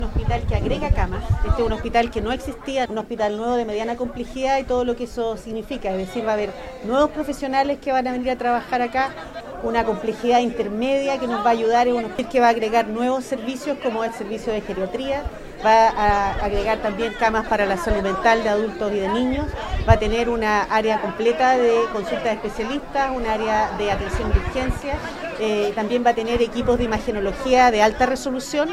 Por su parte, la secretaria de Estado del Minsal, Ximena Aguilera, entregó detalles de los nuevos servicios con los que contará este hospital, dentro de los que destacó el servicio de geriatría, camas para la salud mental de adultos y niños, área de atención de urgencia y equipo de imagenológía de alta resolución.